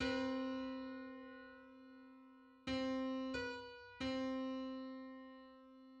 Just: 475/256 = 1070.14 cents.
Public domain Public domain false false This media depicts a musical interval outside of a specific musical context.
Four-hundred-seventy-fifth_harmonic_on_C.mid.mp3